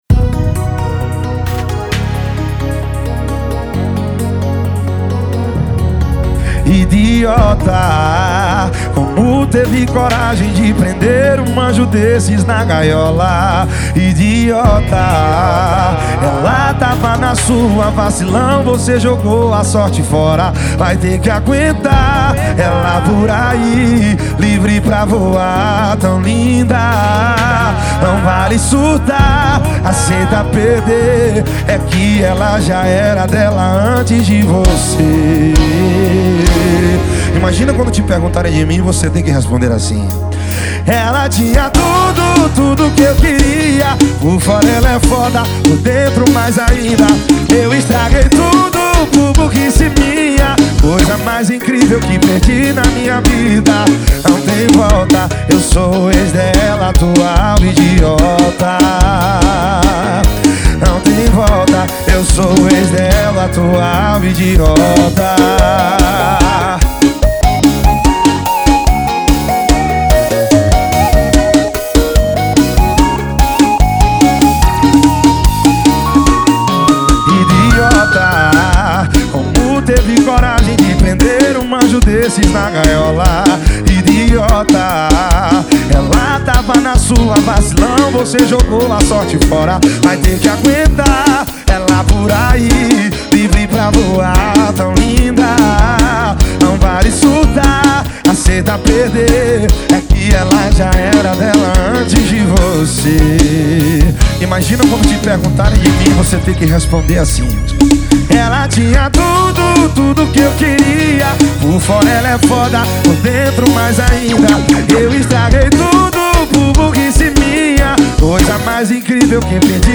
2024-02-14 18:25:22 Gênero: Forró Views